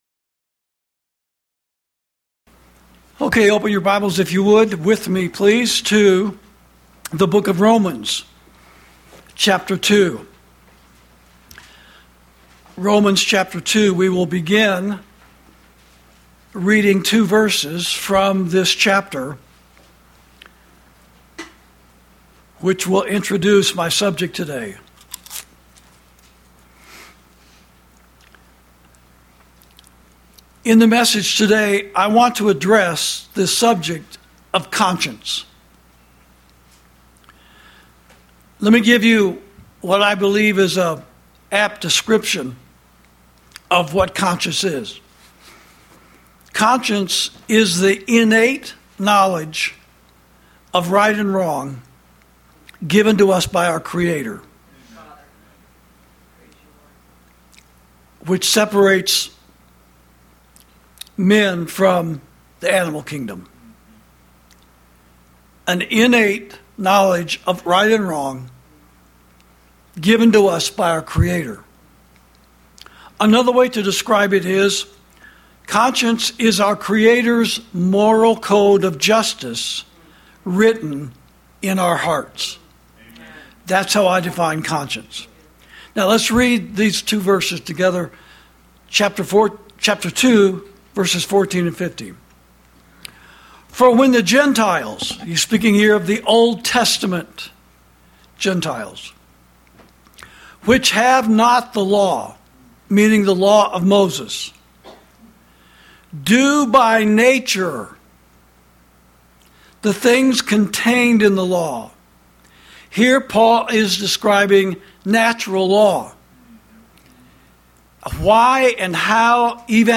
Sermons > The Seared Conscience Of Christian Zionists